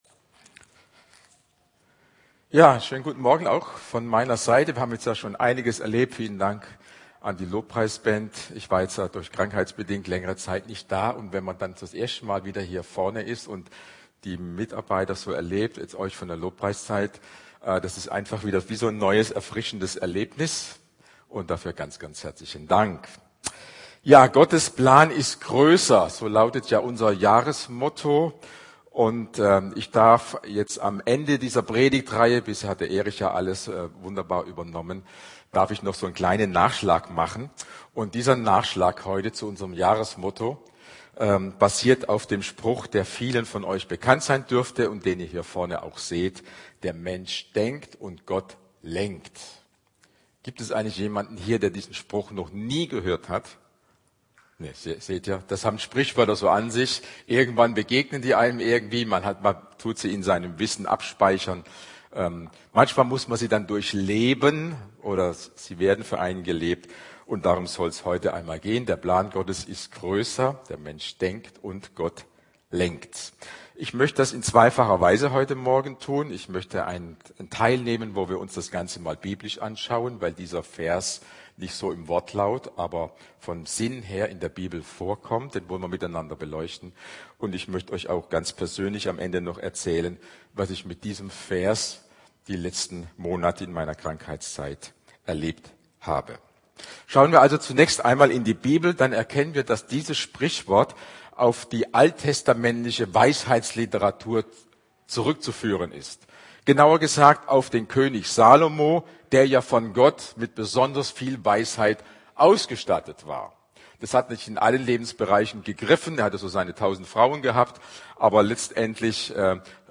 Predigt Sein Plan ist größer